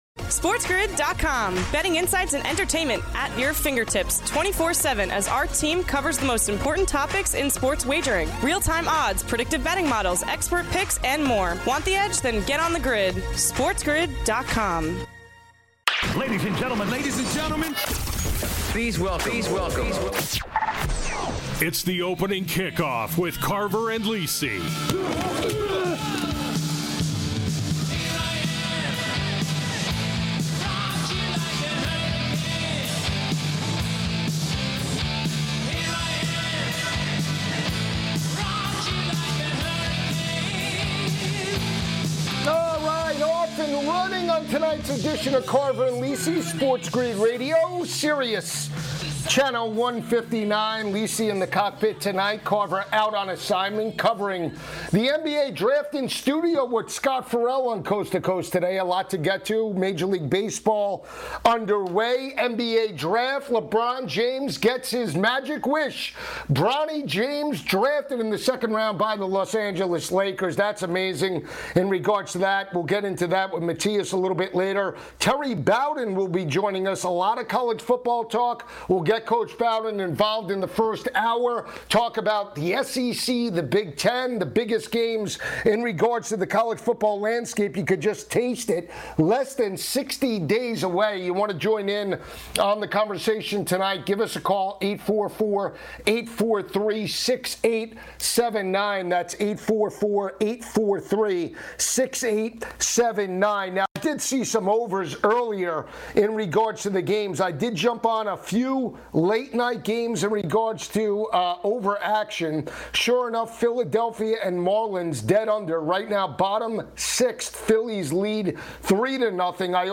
Plus, Coach Terry Bowden joins for college football talk ahead of the start of the upcoming season!